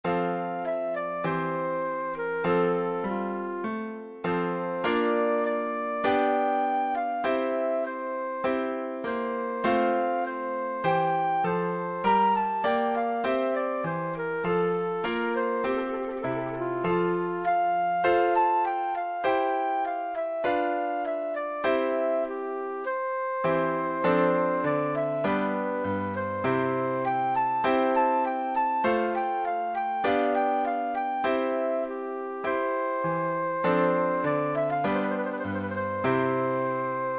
Přednesová skladba pro zobcovou flétnu